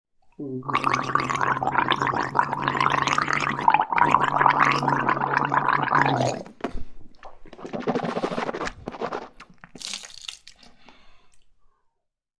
Звуки полоскания горла
Долгий звук